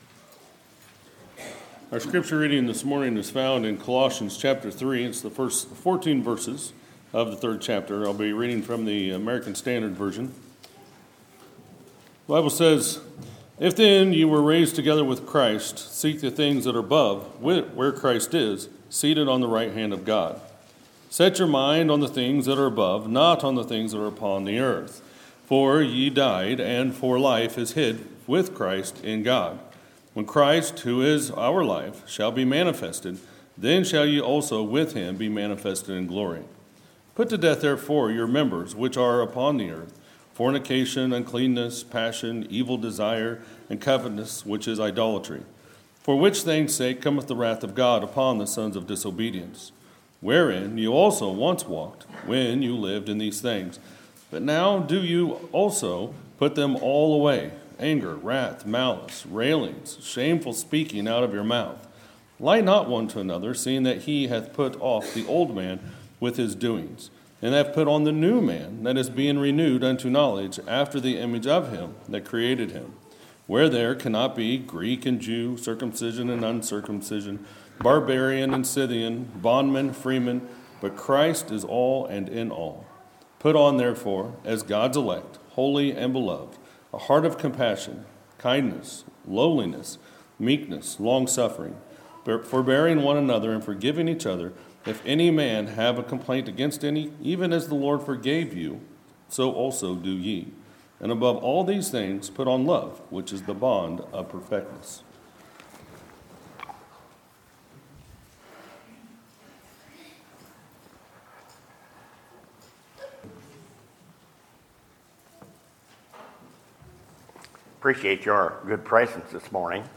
Sermons, June 10, 2018